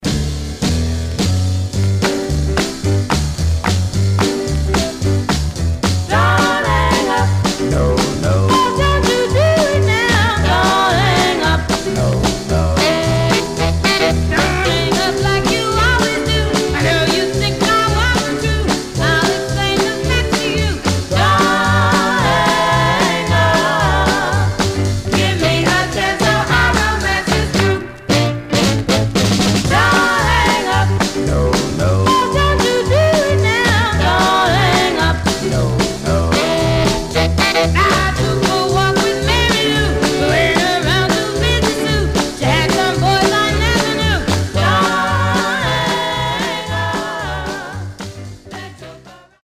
Mono
Black Female Group